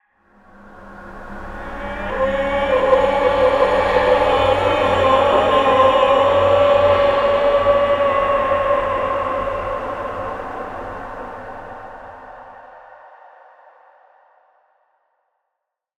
Ghost Manifestation 1.wav